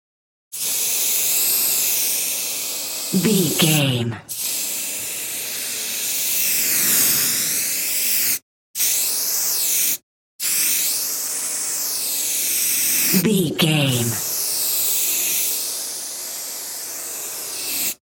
Graffiti aerosol spray large
Sound Effects
foley